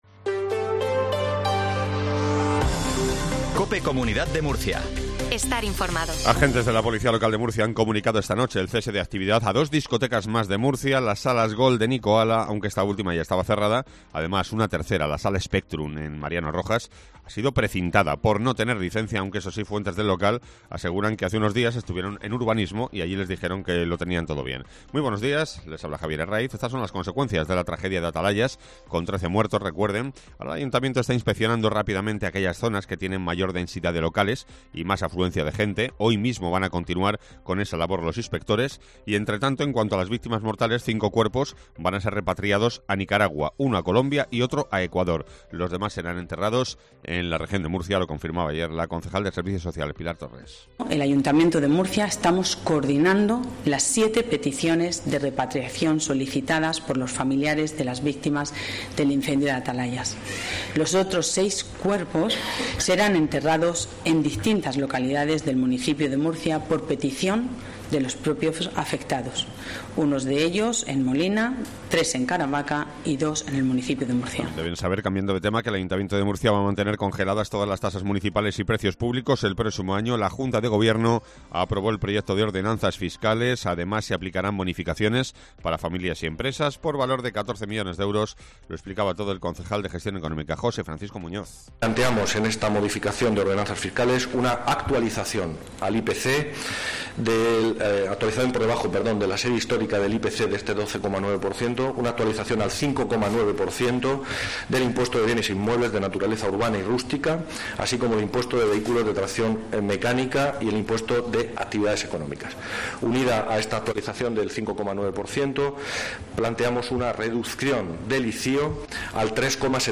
INFORMATIVO MATINAL REGION DE MURCIA 0820